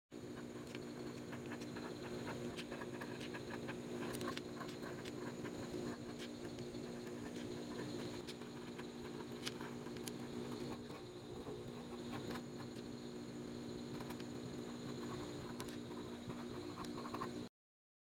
Chalk marker testing…